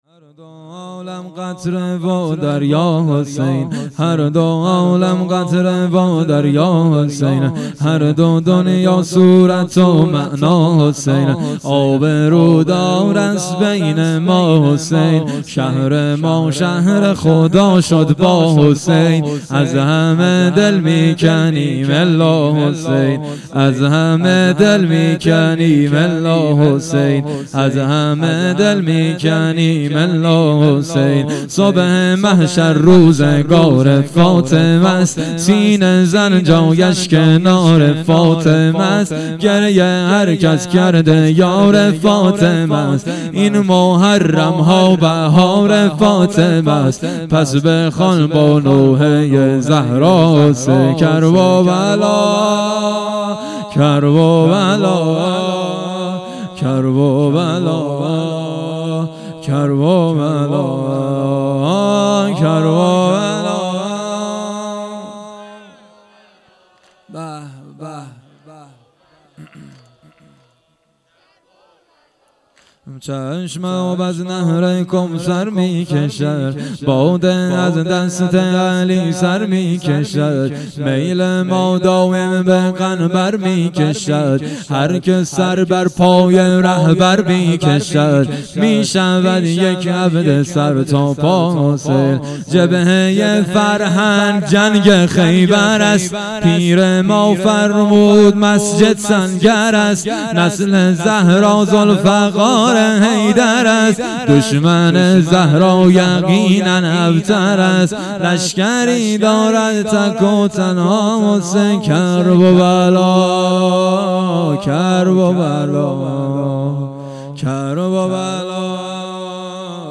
شب دهم - دهه اول محرم 1400